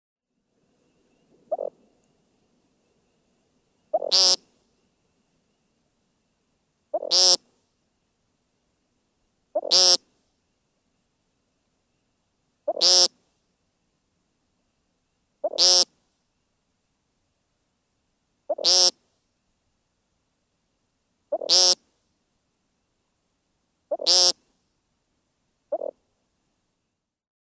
BRD 3284--adult female American Woodcock from NJ